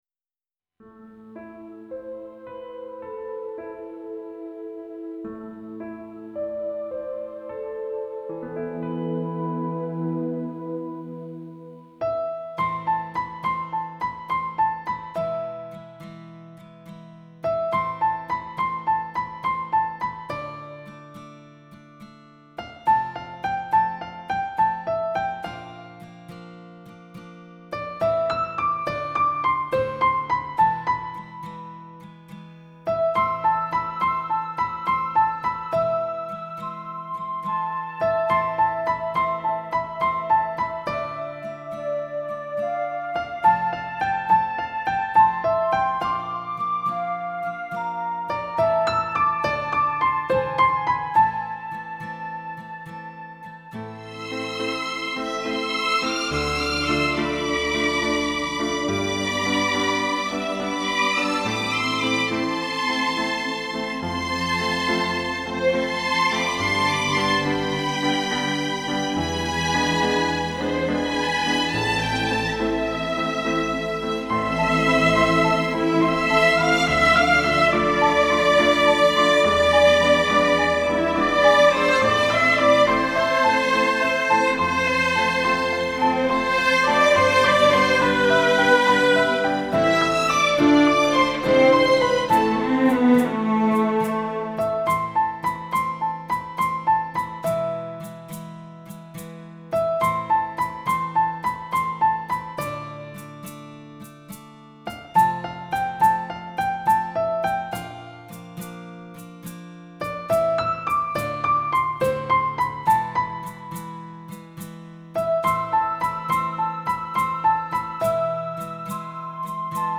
一张具有古典精神的原创音乐唱片
一张具有浪漫色彩的原创音乐唱片